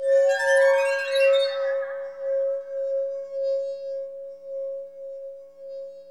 WINE GLAS02R.wav